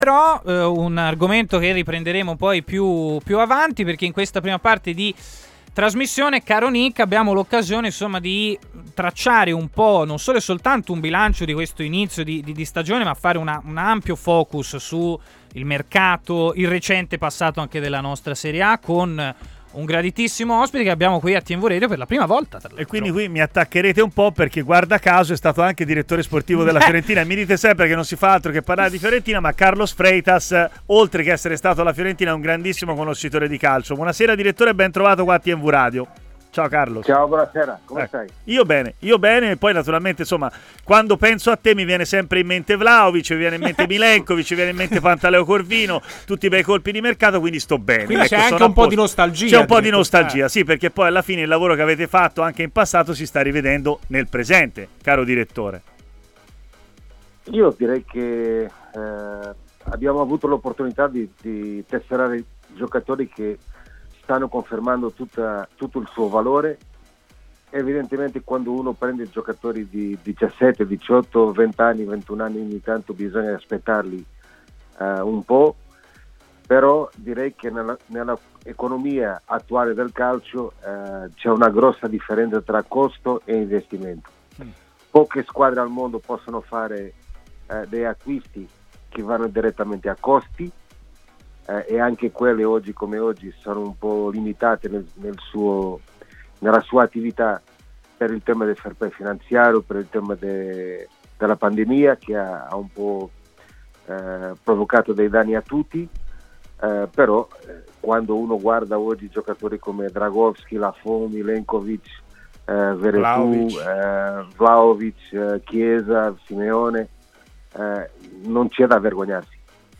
ha così parlato in diretta a TMW Radio , nel corso della trasmissione Stadio Aperto